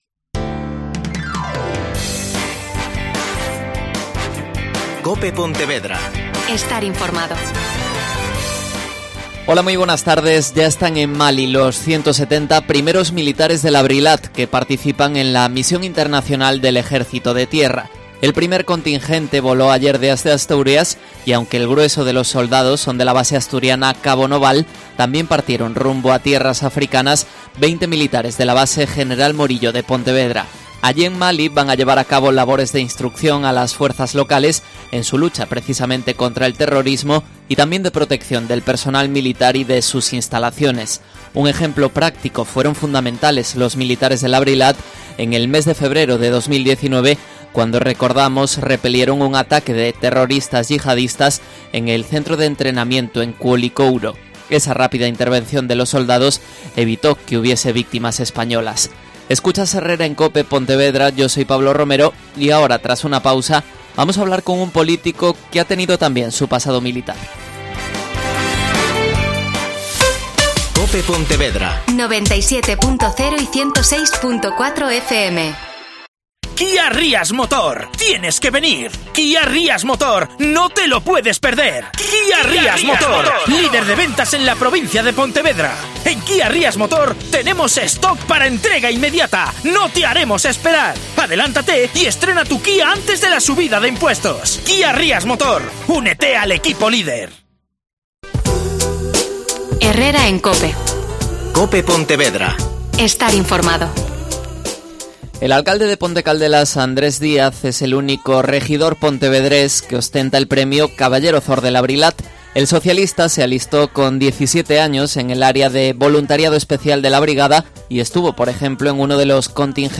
AUDIO: Andrés Díaz. Alcalde de Ponte Caldelas.